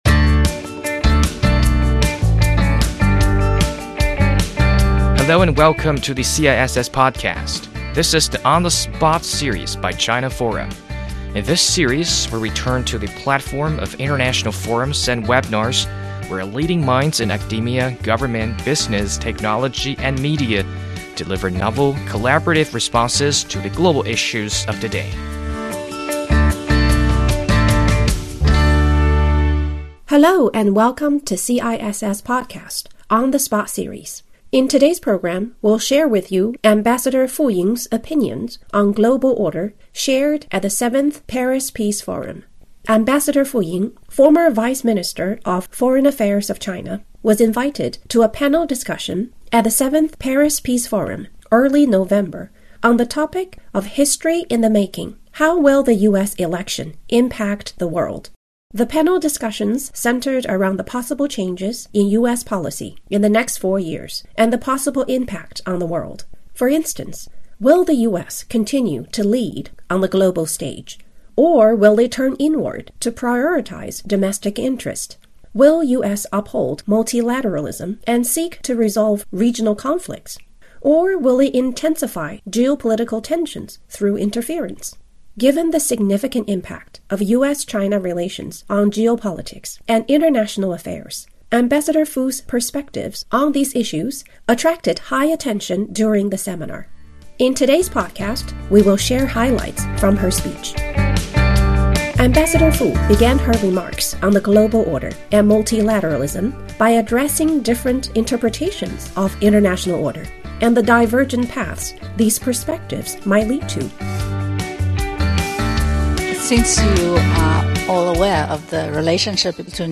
前外交部副部长傅莹日前应邀率团出席第七届巴黎和平论坛，并在主题为“美国大选对全球影响”的分论坛中发言。傅莹大使提出，单靠一国或一组国家无法解决所有的世界难题，未来世界更可能走向多极化。本期播客精选傅莹大使在演讲中关于全球秩序、多边主义与中美关系等问题的观点，以飨听众。